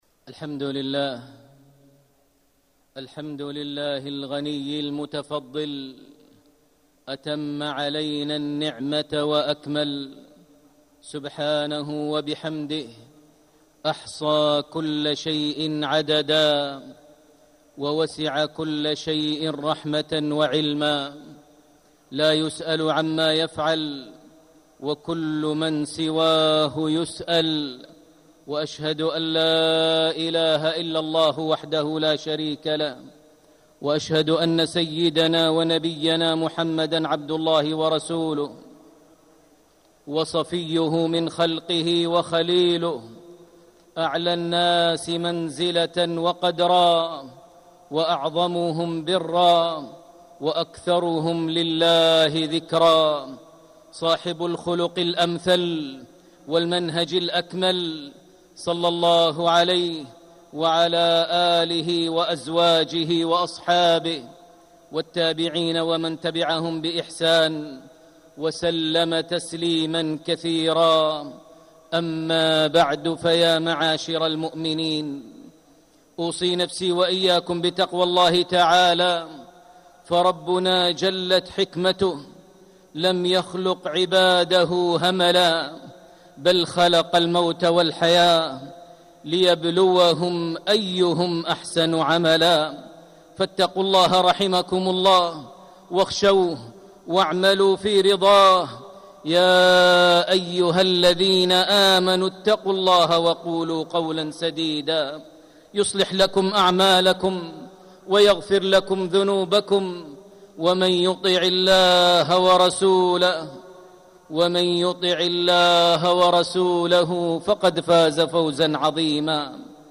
مكة: فضل ومعنى لا حول ولا قوة إلا بالله - ماهر بن حمد المعيقلي (صوت - جودة عالية